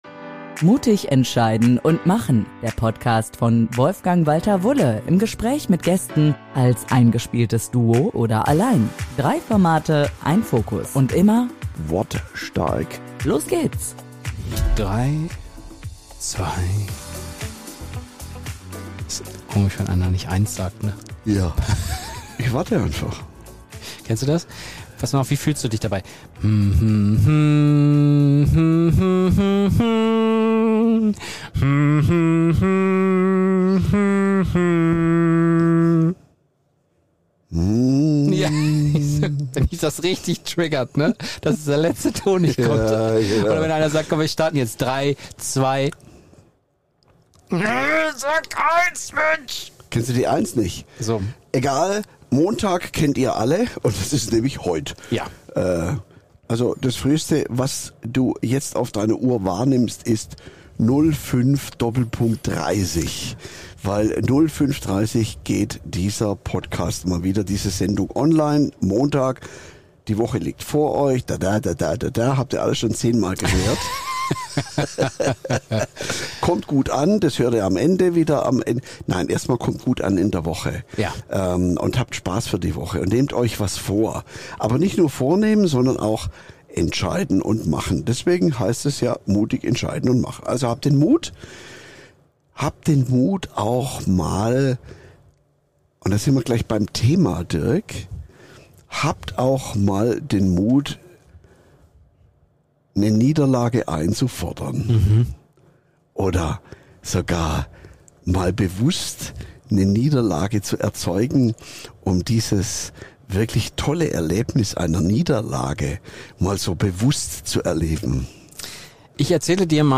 Beschreibung vor 6 Tagen In dieser Folge von MUTIG ENTSCHEIDEN UND MACHEN geht es um einen Aspekt, über den kaum jemand gern spricht: das bewusste Erleben von Niederlagen. Im offenen, ungeskripteten Gespräch